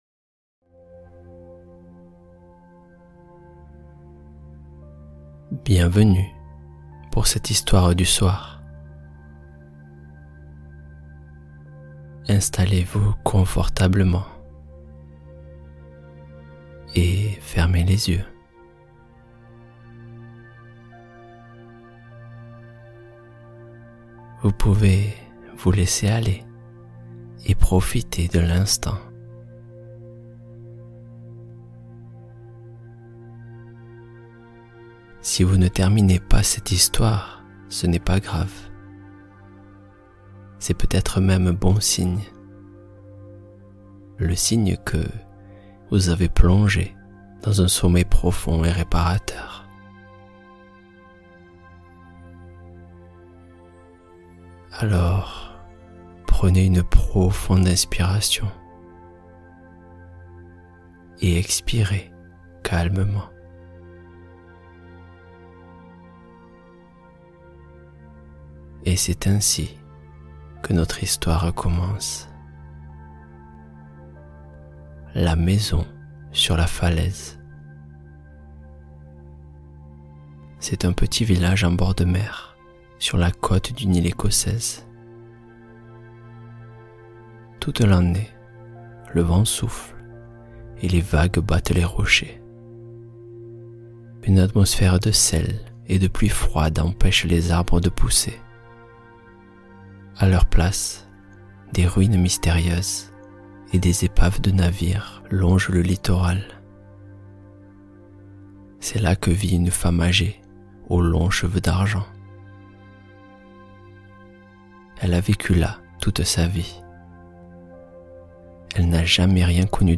Immersion apaisante — Méditation guidée pour le repos